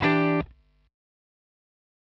Em7_1.wav